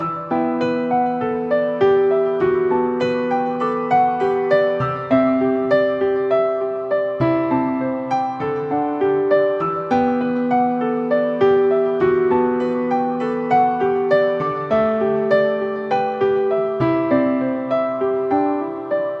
EMINOR中的钢琴循环；希望你觉得这个有用。
Tag: 100 bpm RnB Loops Piano Loops 3.23 MB wav Key : E